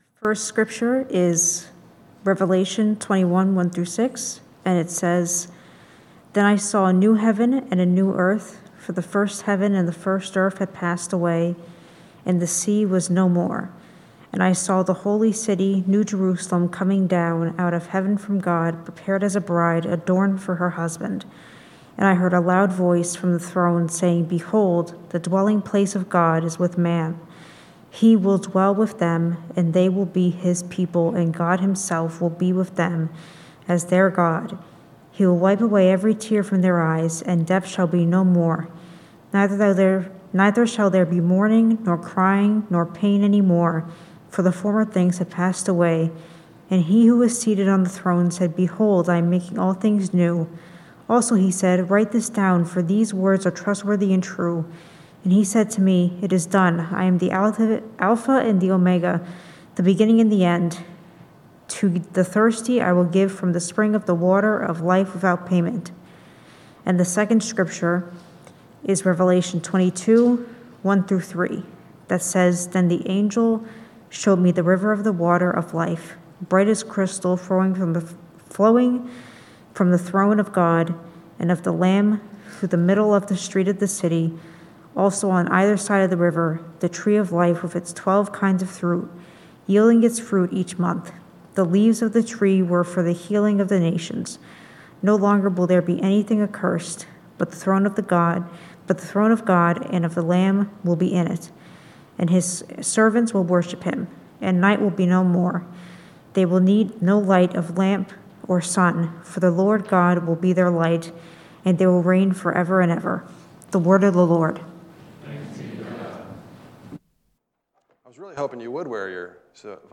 Biblical sermon on faith, politics, and how our promised future changes how we live today from Revelation 21:1-6; 22:1-5.